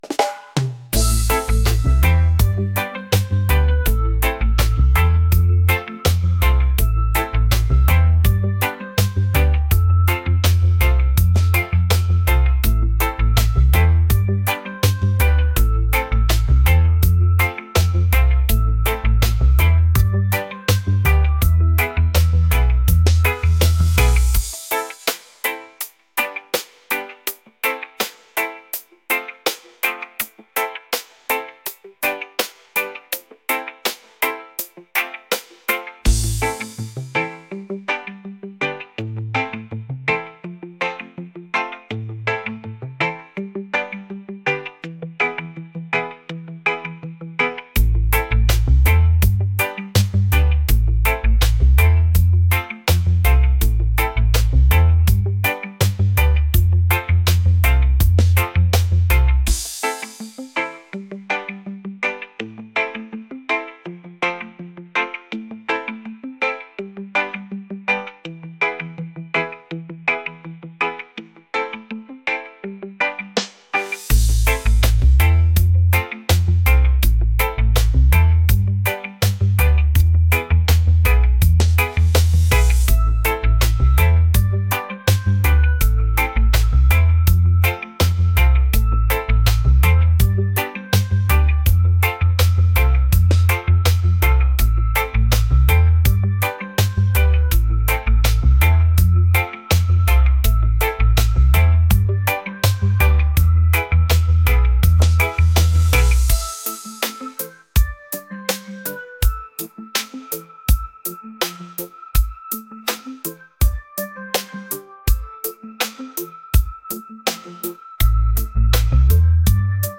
romantic | reggae | laid-back